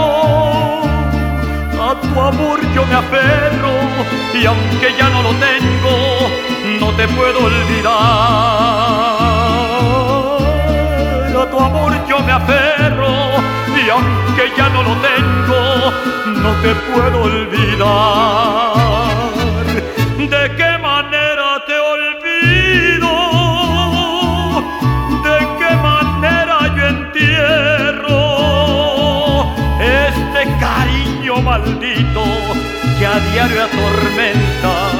Música Mexicana, Latin